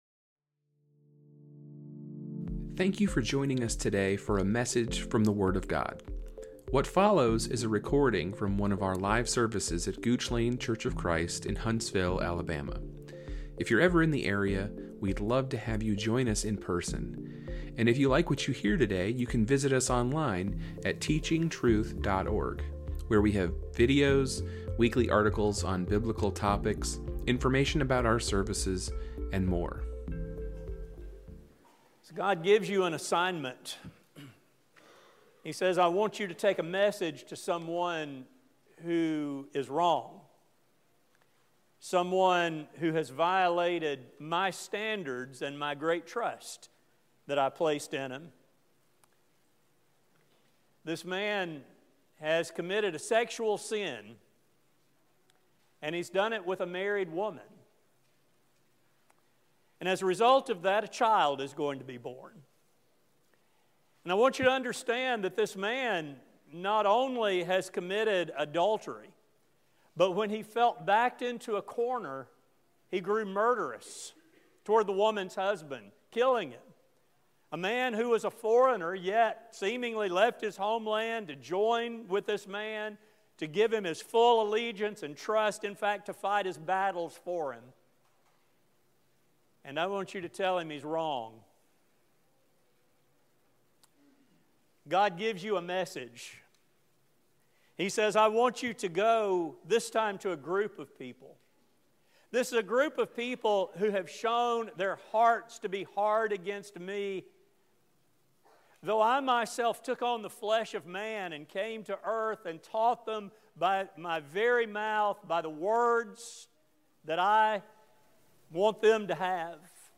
This sermon will demonstrate how the condition of our hearts answers this question. It will also discuss how God mercifully breaks our hearts to make us spiritually whole.